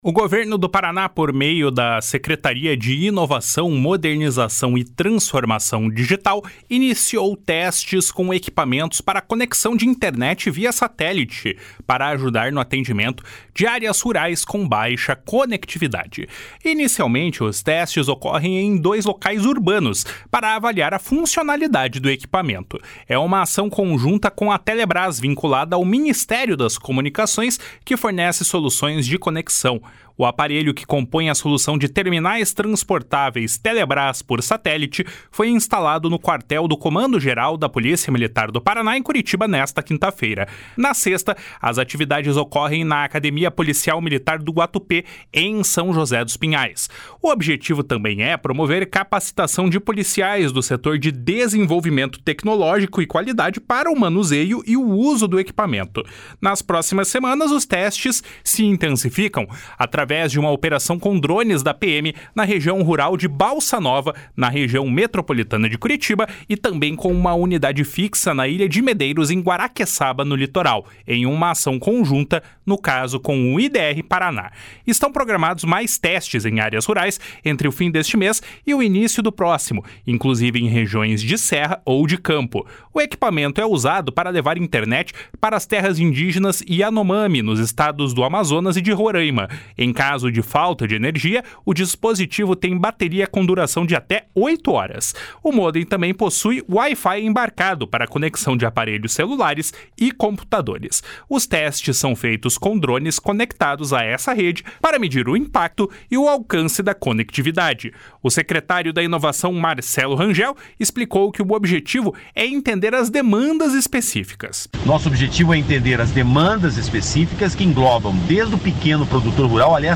Os testes são feitos com drones conectados a essa rede para medir impacto e alcance da conectividade. O secretário da Inovação, Marcelo Rangel, explicou que o objetivo é entender as demandas específicas. // SONORA MARCELO RANGEL //